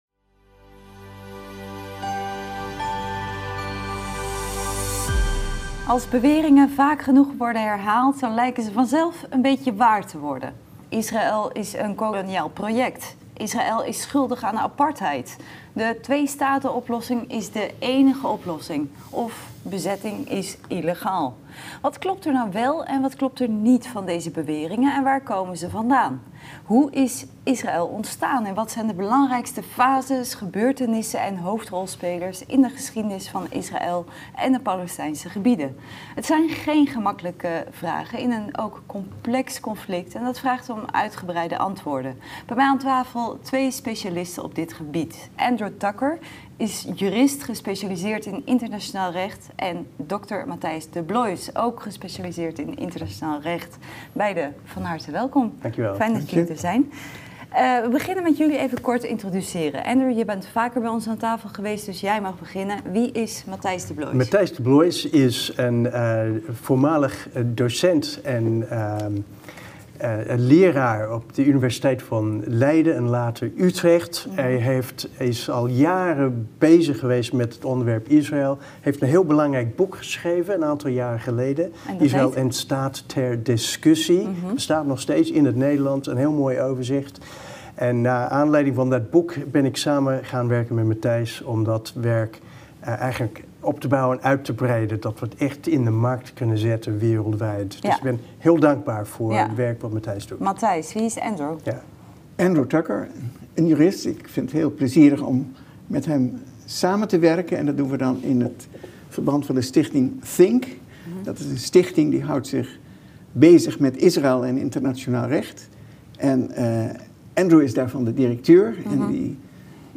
Daarom vandaag aan tafel twee specialisten op gebied van internationaal recht